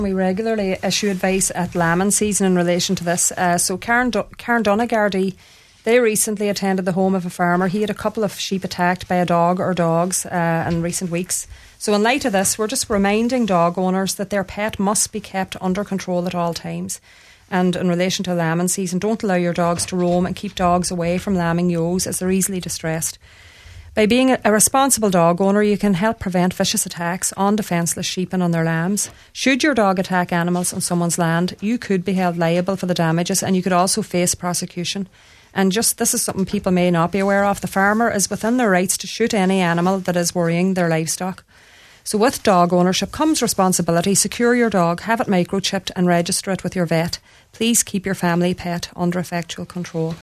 made this appeal on today’s Nine til Noon Show…….